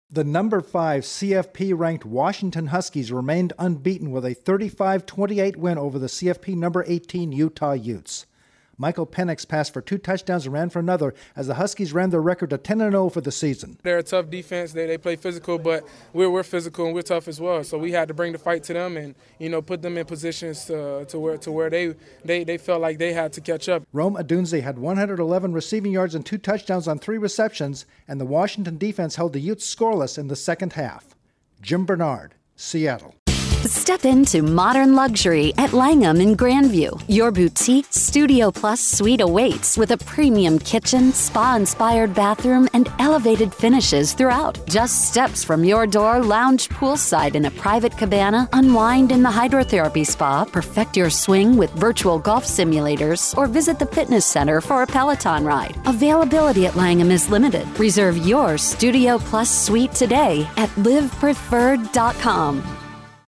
Washington makes another case for a CFP berth. Correspondent